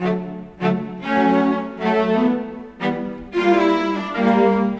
Rock-Pop 10 Cello _ Viola 01.wav